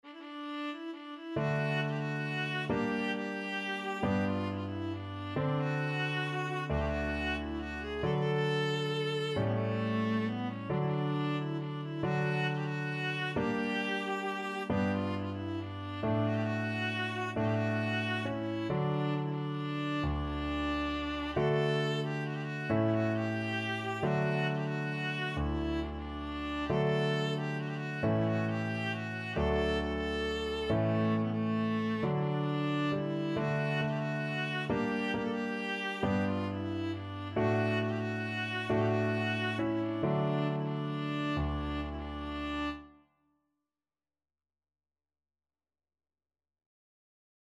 World Trad. Joc In Patru (Romanian Folk Song) Viola version
Viola
Traditional Music of unknown author.
3/4 (View more 3/4 Music)
D major (Sounding Pitch) (View more D major Music for Viola )
One in a bar .=45
romania_joc_in_patru_VLA.mp3